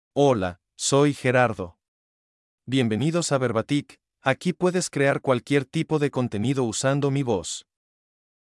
MaleSpanish (Mexico)
Gerardo — Male Spanish AI voice
Voice sample
Listen to Gerardo's male Spanish voice.
Gerardo delivers clear pronunciation with authentic Mexico Spanish intonation, making your content sound professionally produced.